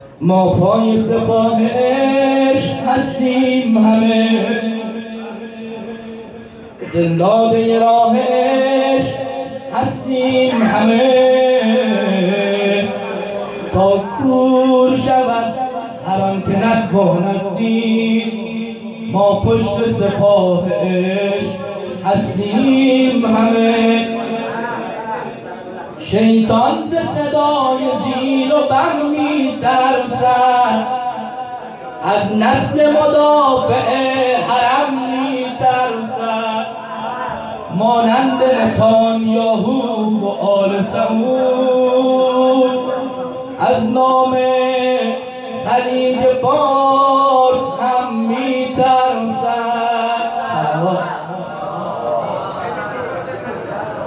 مراسم جشن نیمه شعبان 98 هیئت صادقیون(ع)زابل